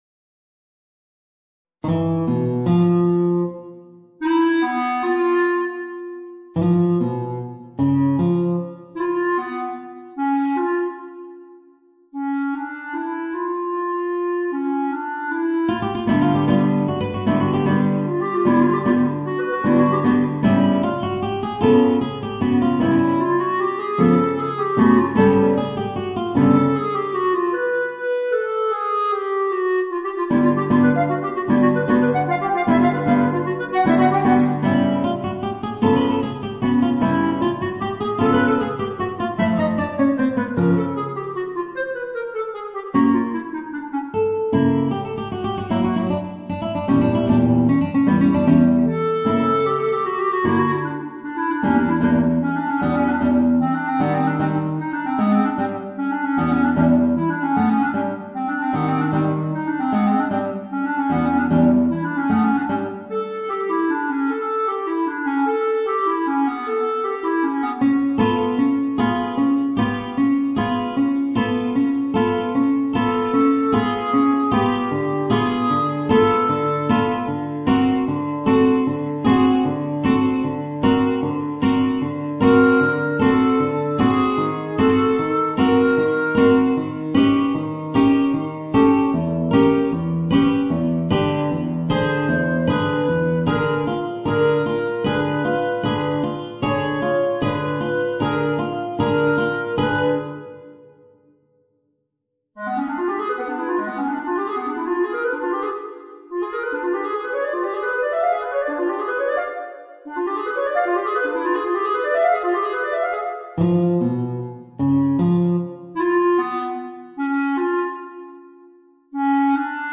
Voicing: Guitar and Clarinet